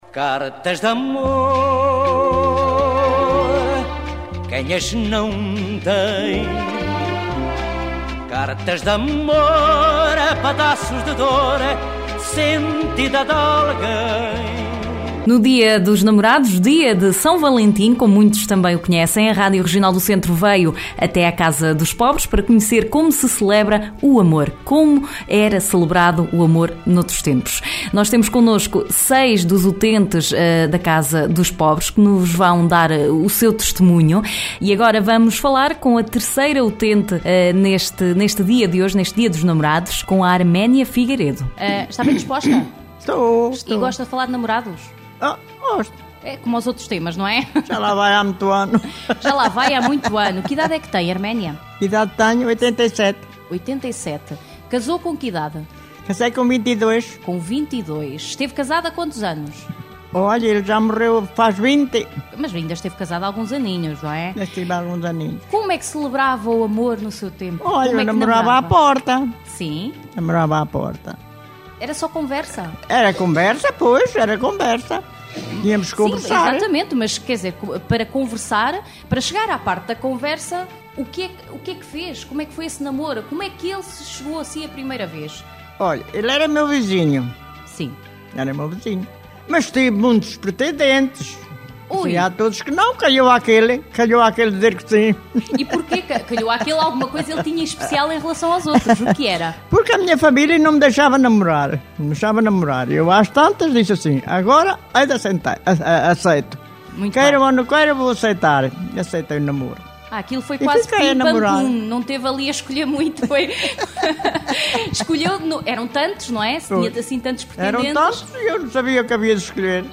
A Regional do Centro foi à Casa dos Pobres, em Coimbra, para saber junto de alguns utentes como se celebrava o amor há algumas décadas atrás. Oiça aqui um dos testemunhos.